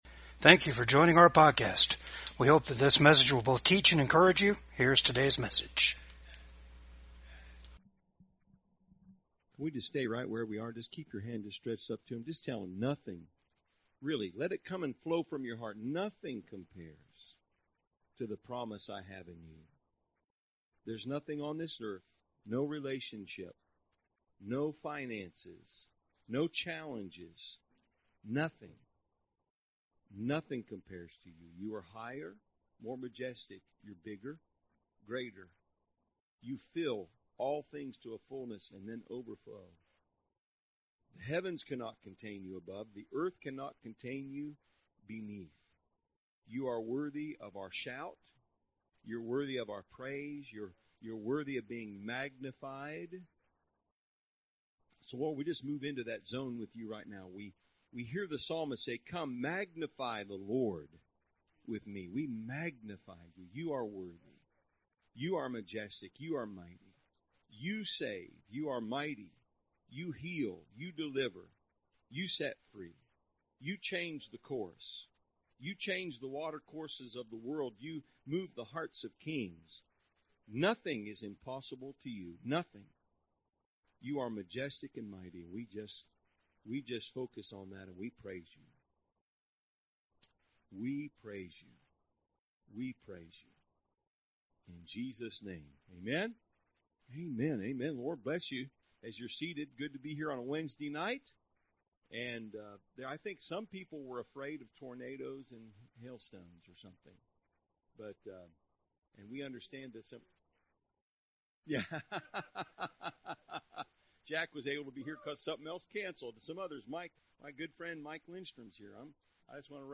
1 John 2:20 Service Type: REFRESH SERVICE LEARN TO WAIT UPON THE LORD.